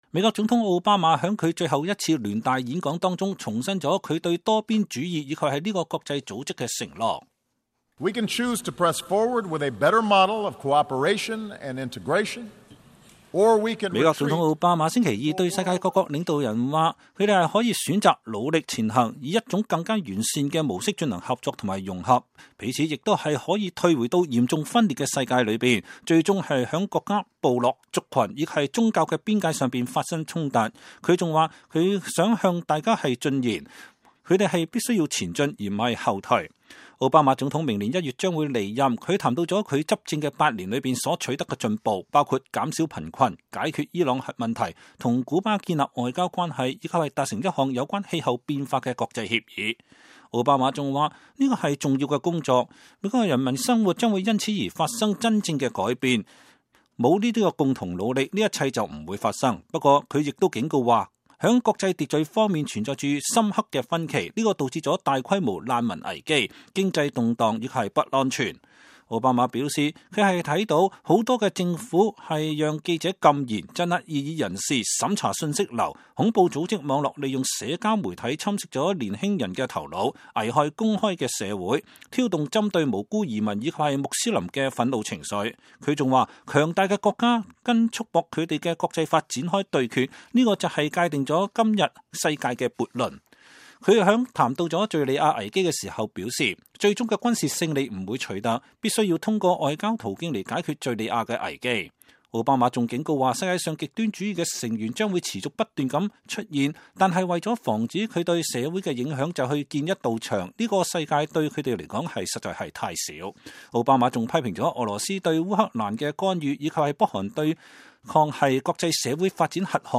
美國總統奧巴馬在紐約聯合國大會上發表講話。